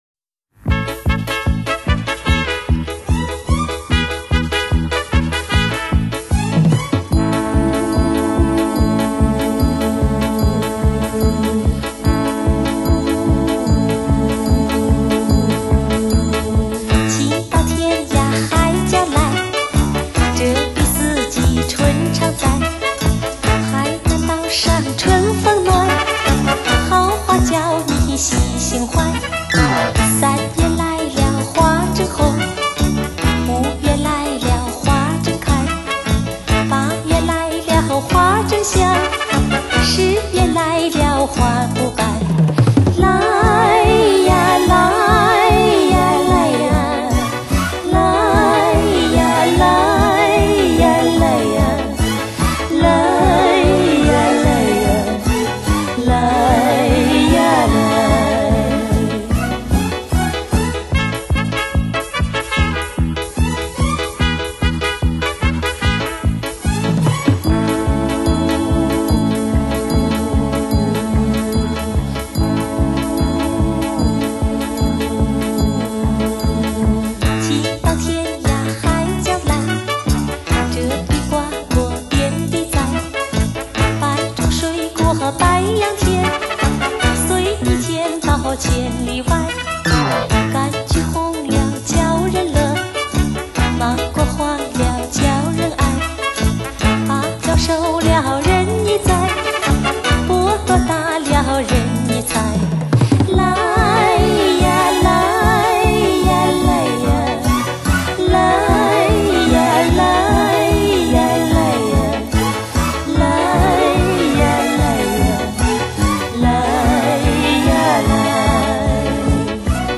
首首都是80年代风云作品，一张惊人的模拟录音靓碟，众多的“烧家”名器已被“烧糊”，亲身感受过的乐迷们为此津津乐道！
比普通XRCD、SACD更真实、无失真、无音染的内容绝对举世闻名、极度发烧。